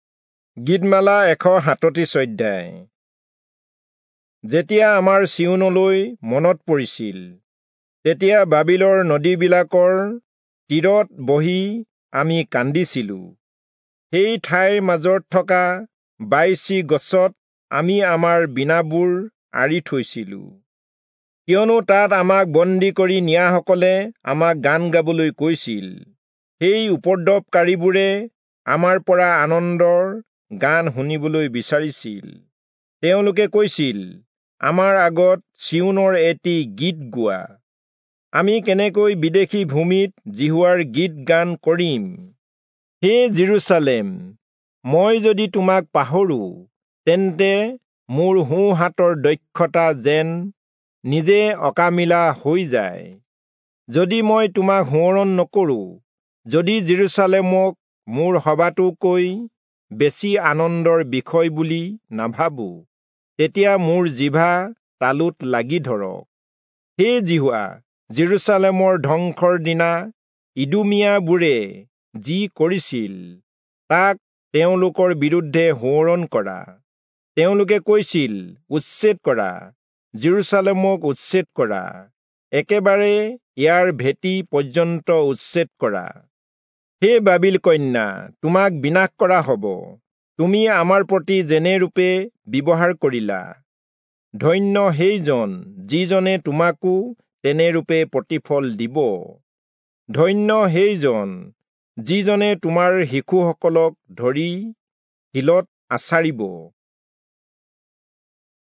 Assamese Audio Bible - Psalms 32 in Kjv bible version